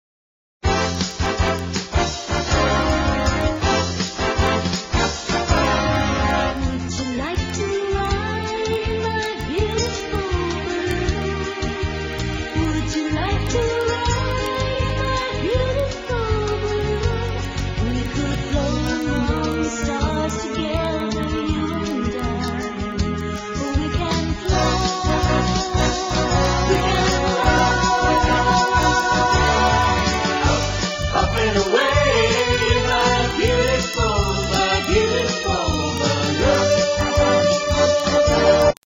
NOTE: Vocal Tracks 1 Thru 6